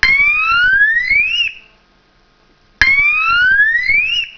AVISADOR ACÚSTICO TELEFÓNICO 3 SONIDOS
Tono_3 modulado
Tono_3 modulado.wav